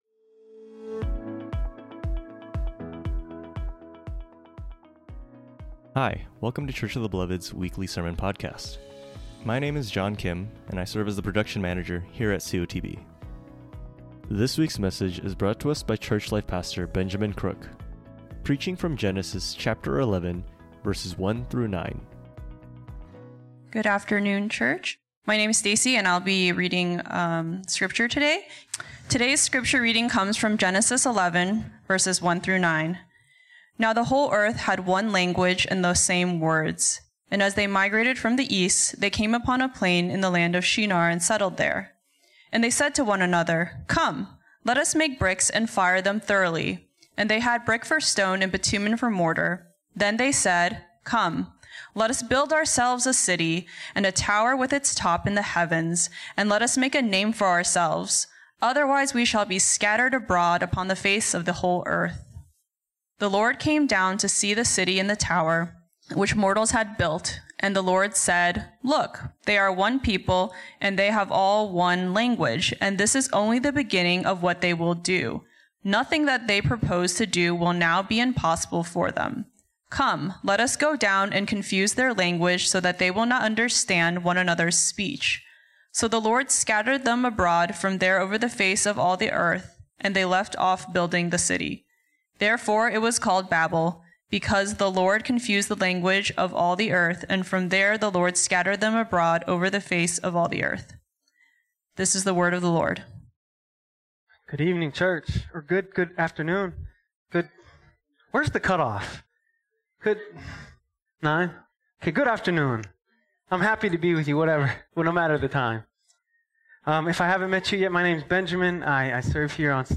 preaches